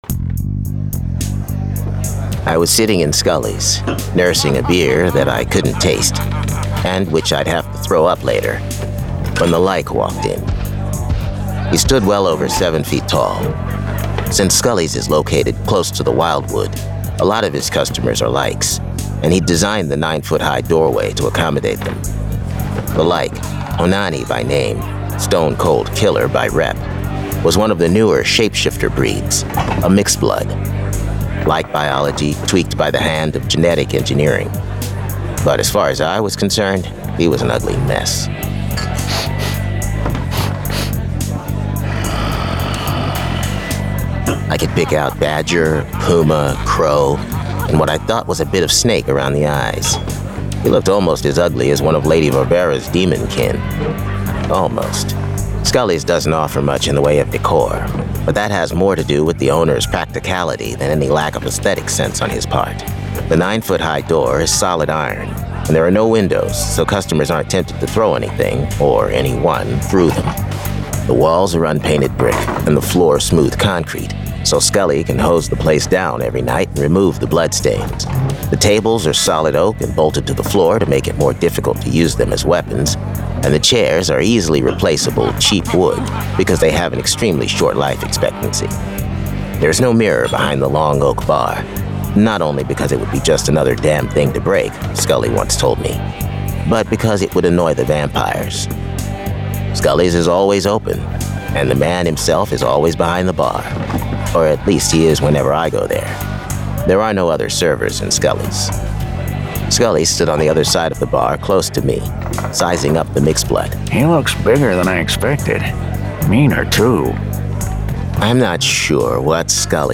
Nekropolis 1: Meet Matt Richter - Private Eye - Zombie [Dramatized Adaptation]